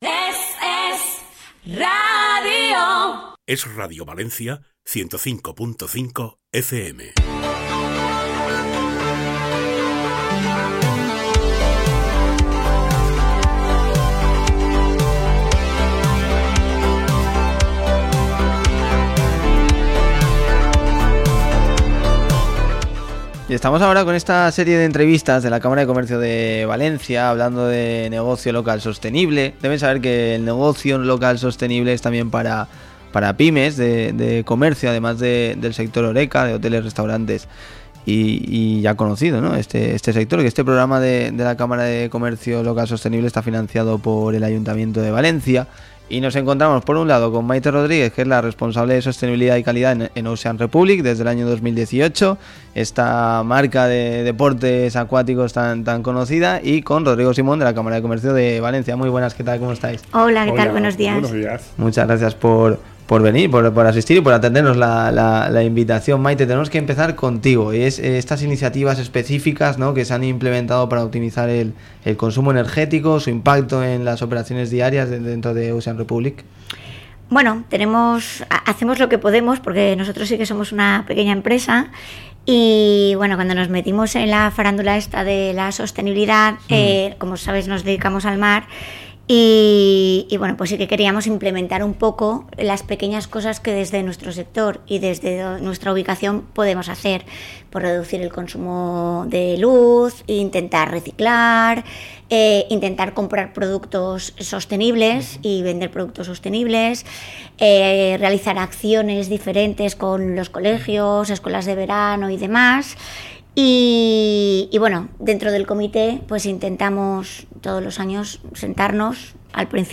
Entrevista con Ocean Republik